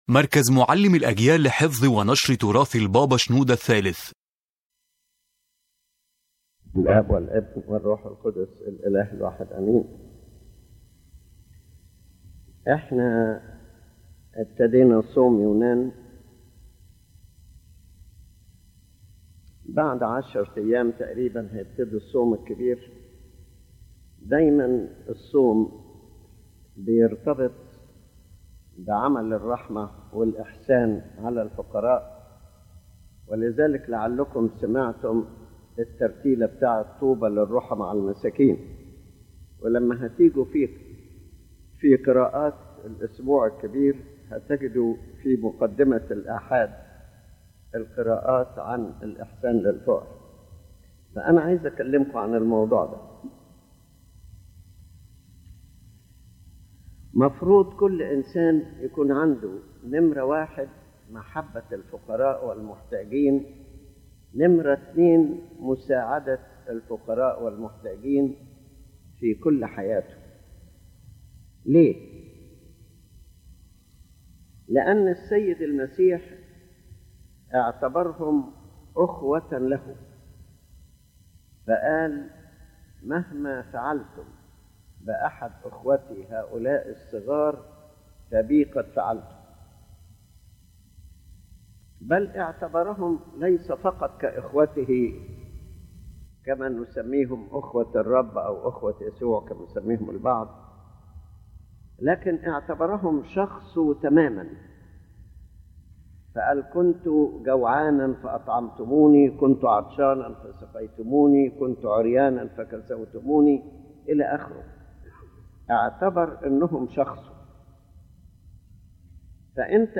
The lecture focuses on the importance of mercy toward the poor as an essential part of the spiritual life, especially connected with fasting, since true fasting cannot be separated from acts of mercy and giving.